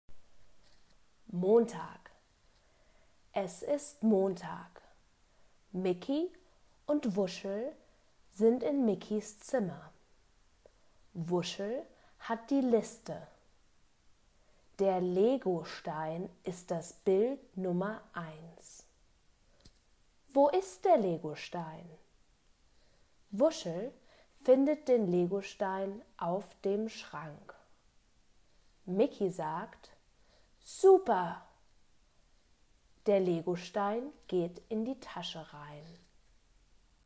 Text read: Begleitheft p.18(MP3, 593 KB)